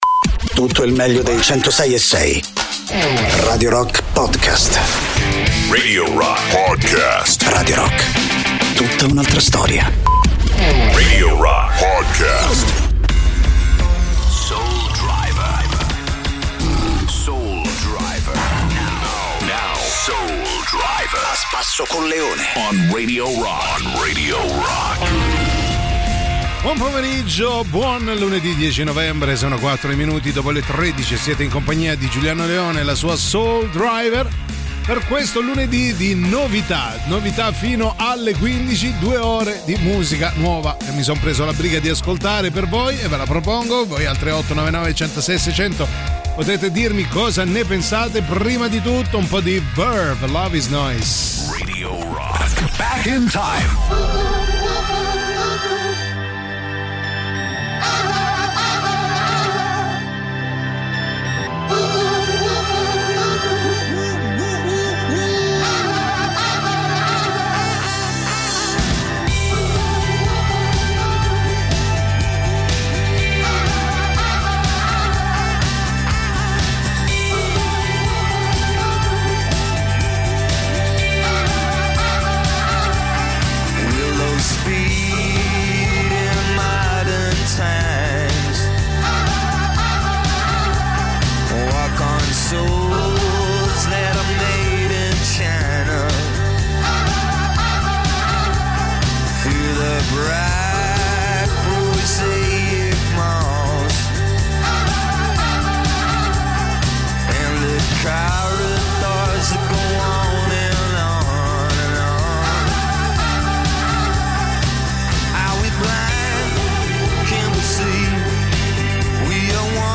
in diretta dal lunedì al venerdì, dalle 13 alle 15